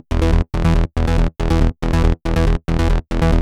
VTS1 Selection Kit Bassline